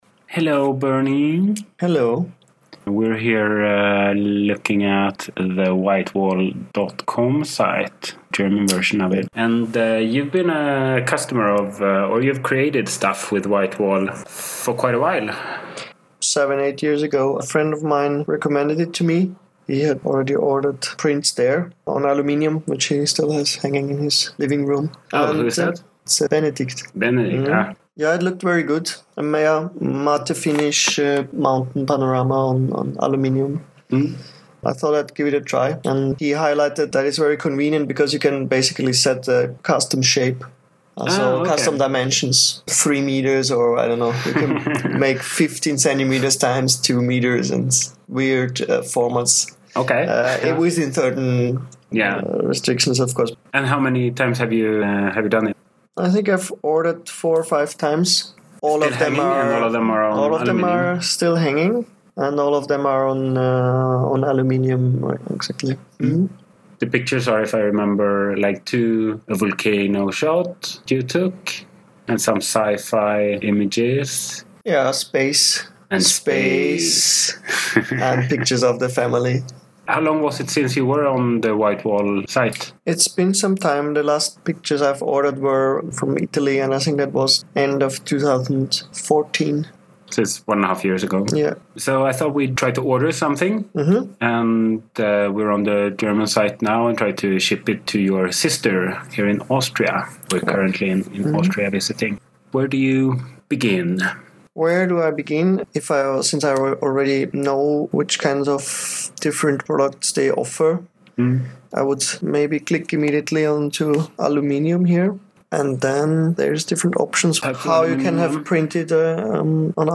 I have edited the recording for brevity but tried to stay true to the experience we had :)